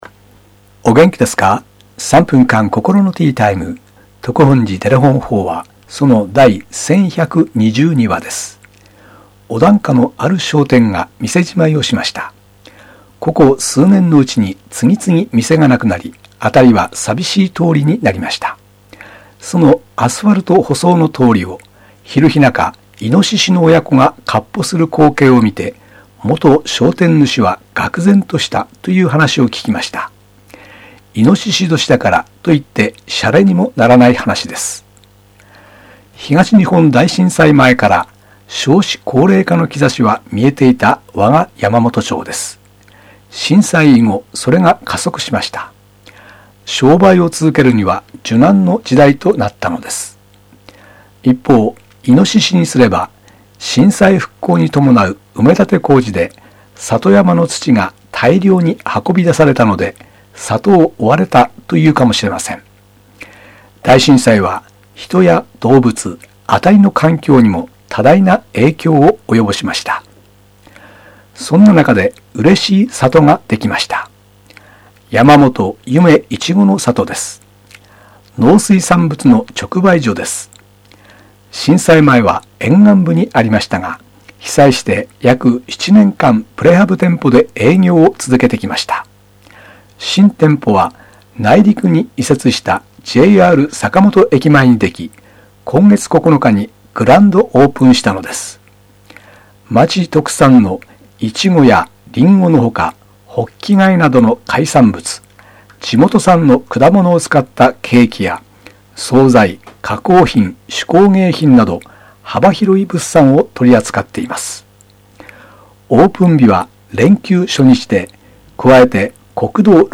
テレホン法話
住職が語る法話を聴くことができます